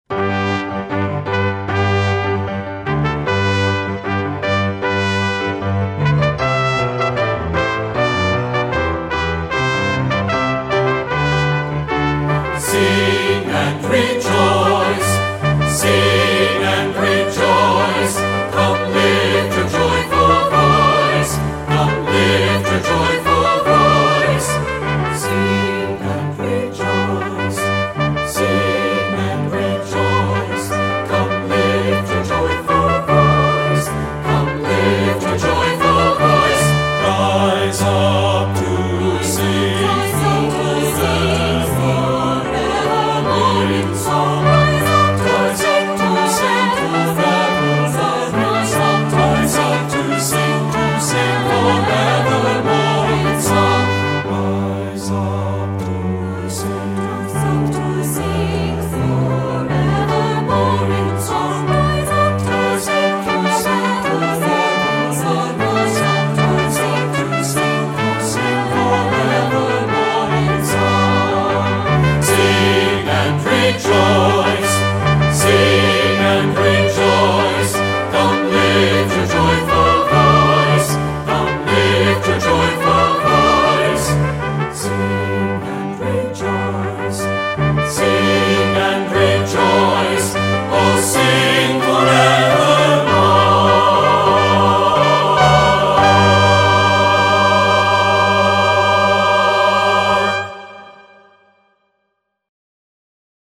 Voicing: SAB